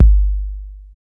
808 THUDK F.wav